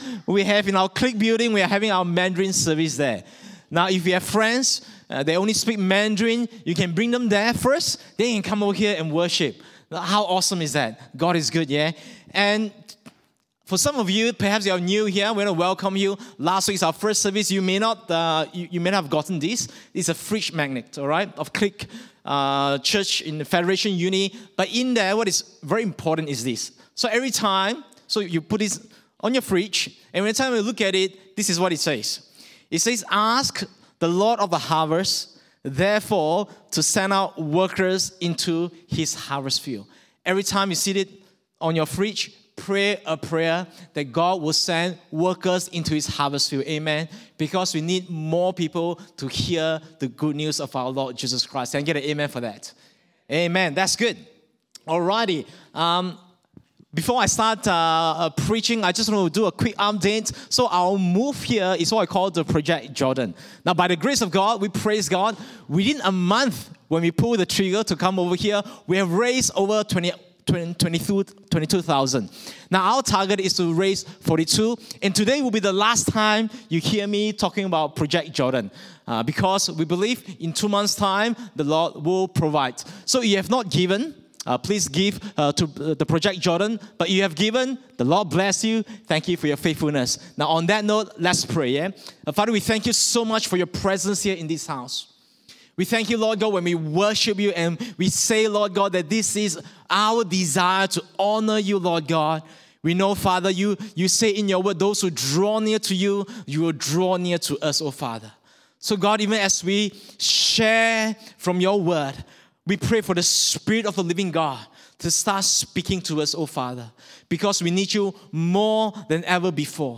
English Sermons | Casey Life International Church (CLIC)
English Worship Service - 12 June 2022